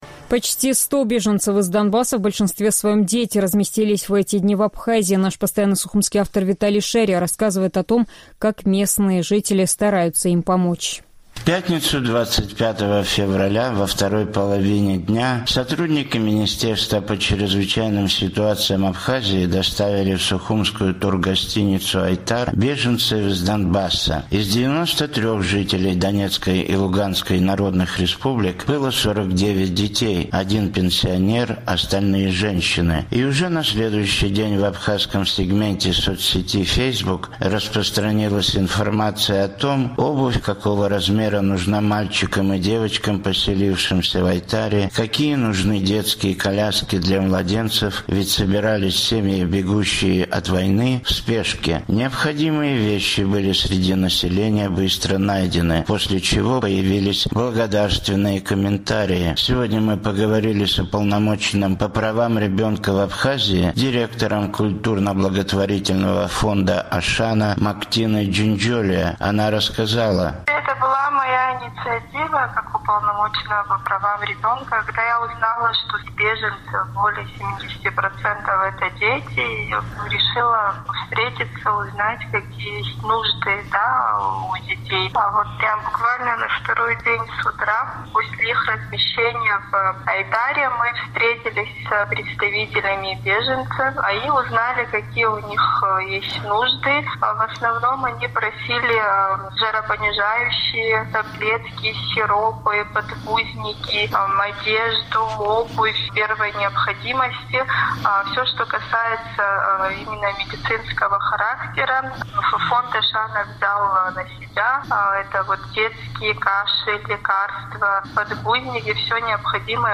«Эхо Кавказа» поговорило с Уполномоченным по правам ребенка в Абхазии, директором культурно-благотворительного фонда «Ашана» Мактиной Джинджолия, которая возглавила и координировала сбор гуманитарной помощи: